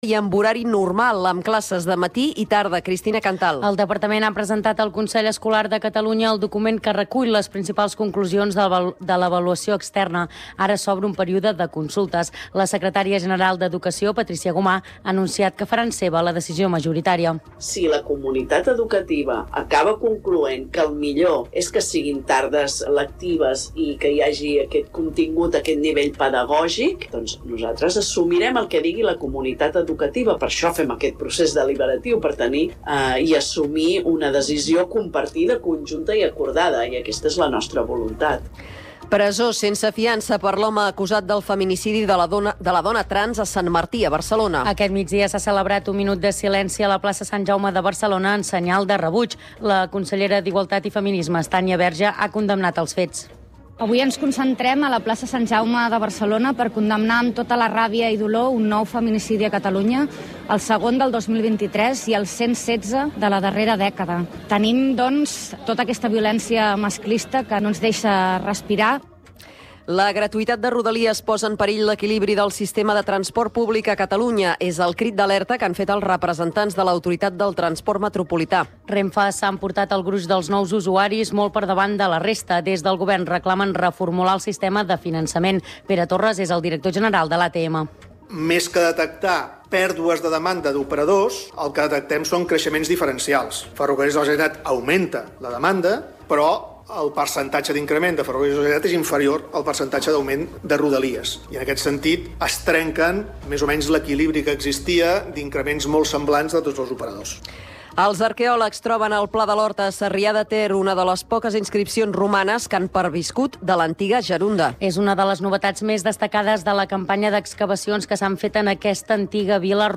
Informatiu en xarxa que fa difusió nacional dels fets locals i ofereix la visió local dels fets nacionals.